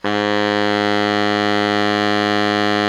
Index of /90_sSampleCDs/Giga Samples Collection/Sax/GR8 SAXES MF
BARI  MF G#1.wav